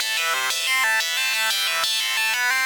Index of /musicradar/shimmer-and-sparkle-samples/90bpm
SaS_Arp05_90-A.wav